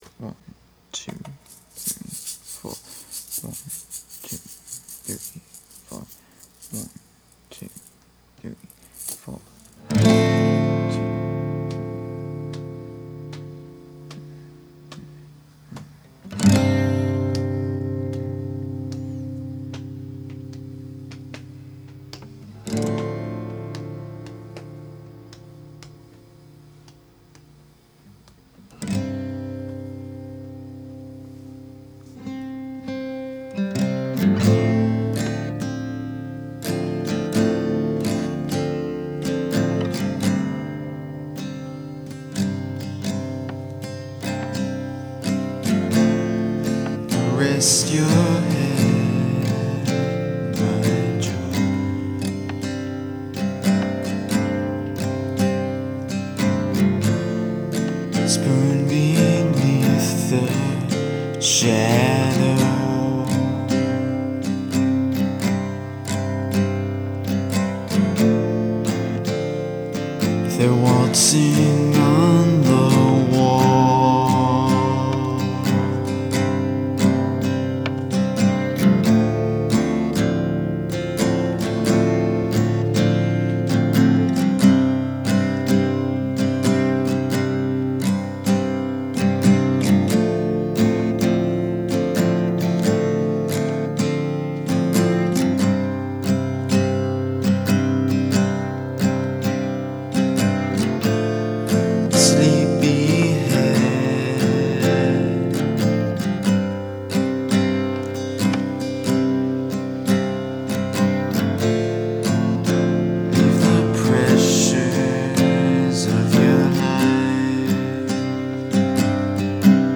Spoon (early demo, July 2004) Dublin Core Title Spoon (early demo, July 2004) Subject Short Wave Description A demo for a song that was never played with the full band.